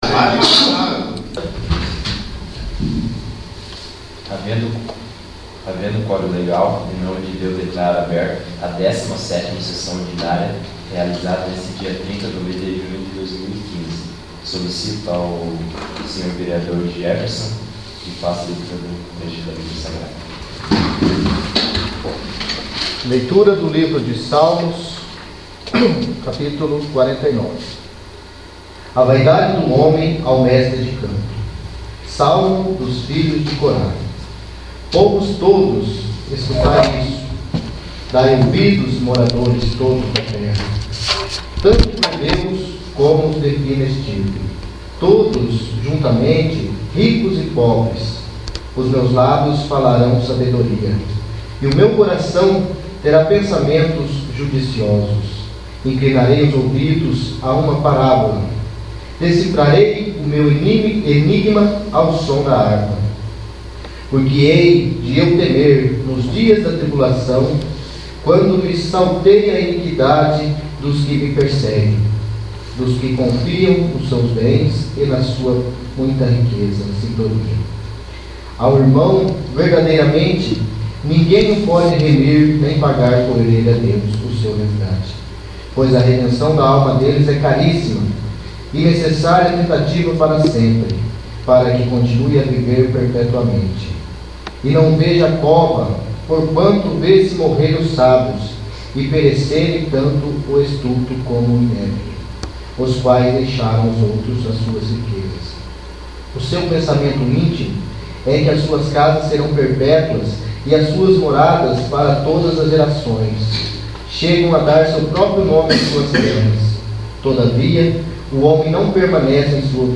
17º. Sessão Ordinária